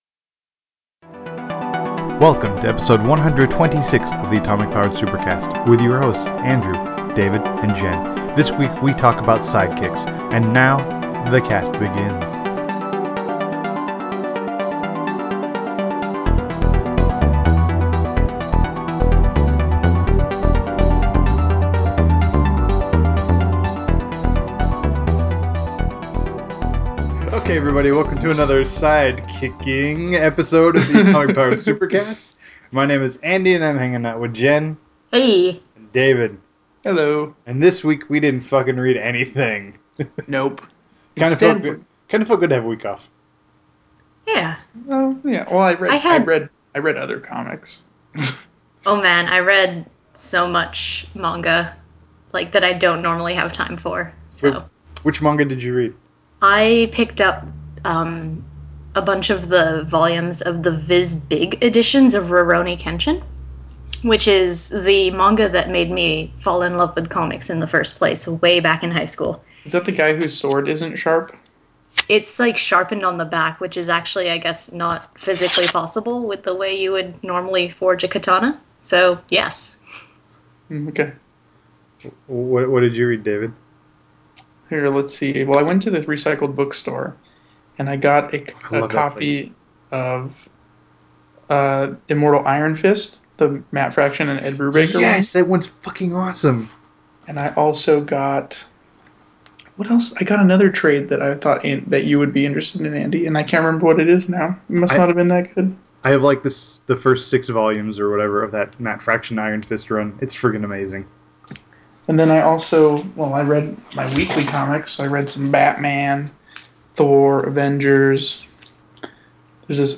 We wrap up our sidekick series with an open discussion on the role and function of sidekicks in comics. Are they necessary anymore? Why were they around in the first place?